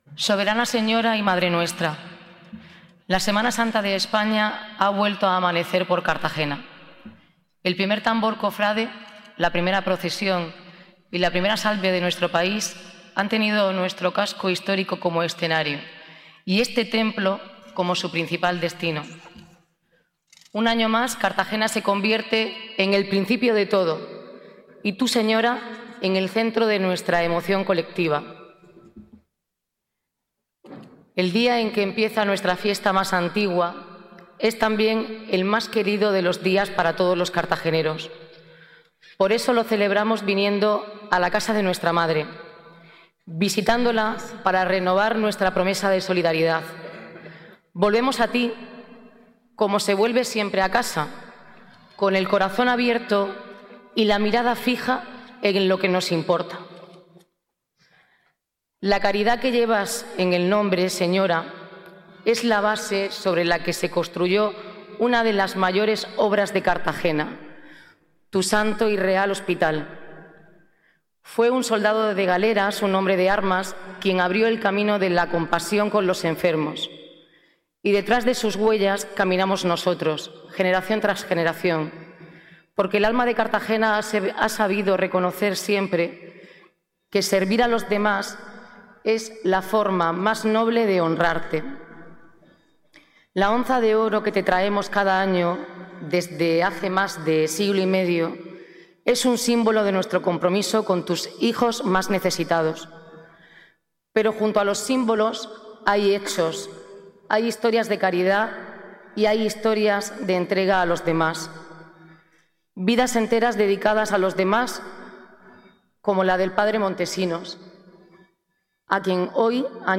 Enlace a Intervención de la alcaldesa, Noelia Arroyo, en la Onza de Oro